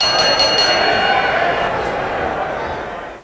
课间&课间铃声（12）-小虎鲸Scratch资源站